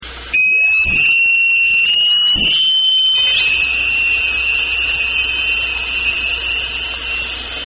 Individual sonar ping at 10:55
Recorded and provided by another hydrophone network citizen scientist:
Spectrogram of a single ping on the Orcasound hydrophone at 10:55 a.m.